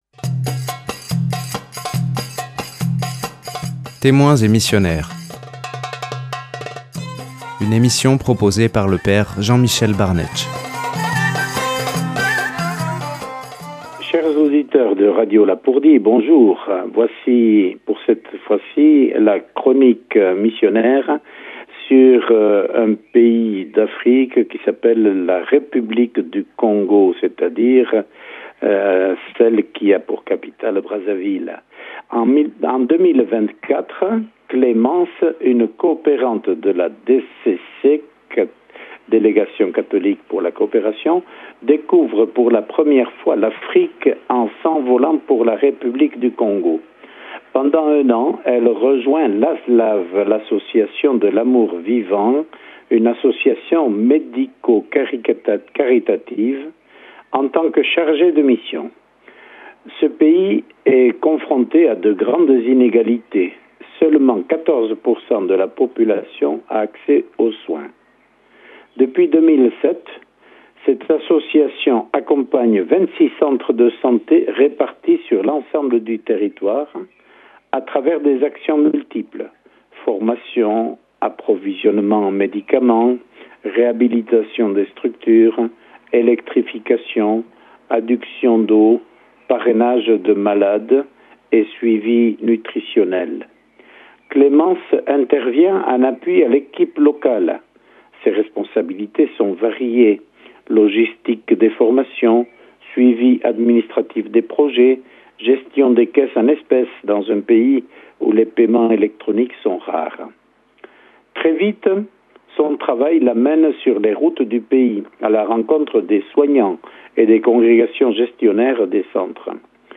Le témoignage d’une volontaire DCC en République du Congo